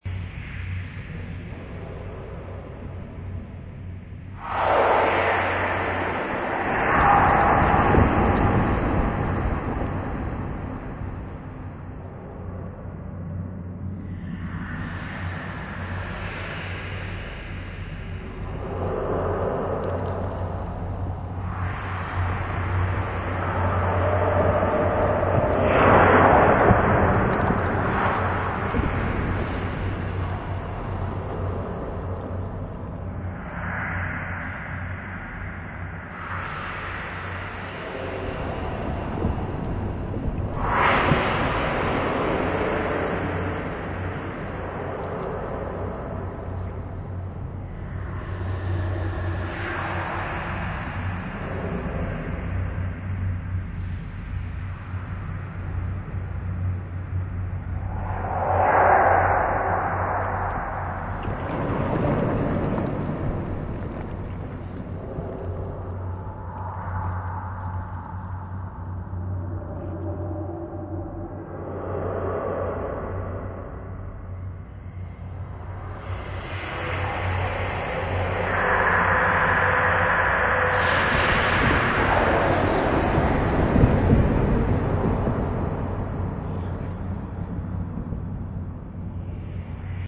AMBIENCES